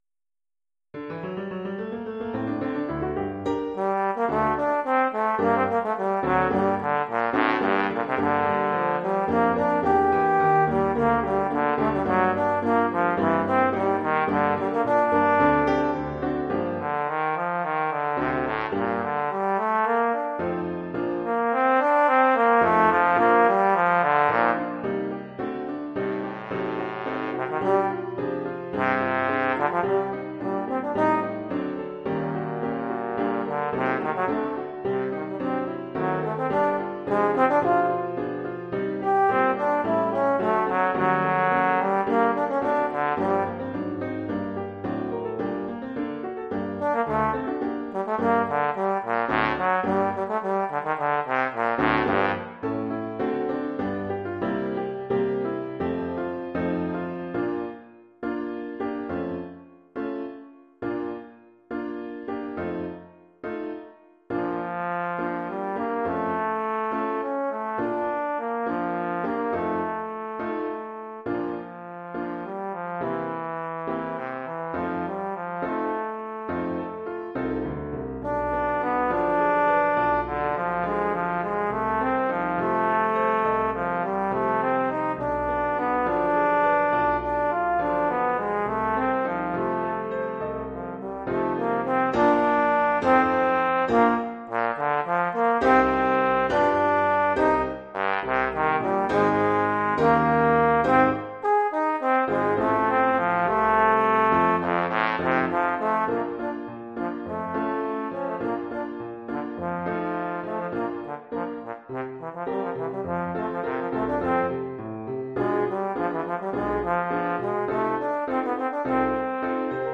Formule instrumentale : Trombone et piano
Oeuvre pour trombone et piano.